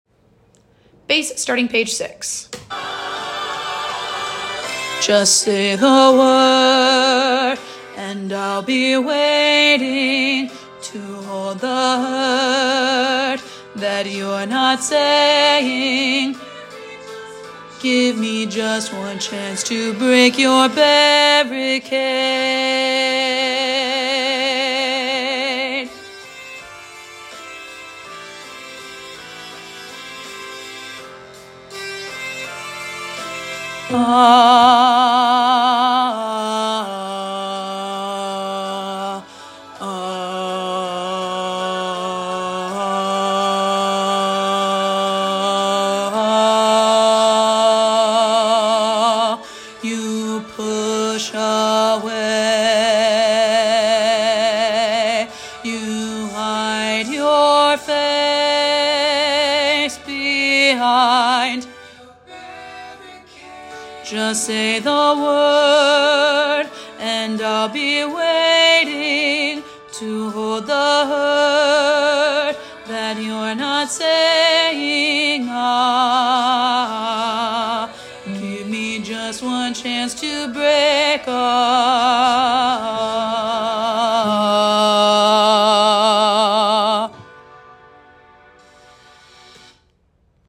with lush harmonies, tall chords, and an epic conclusion.
Bass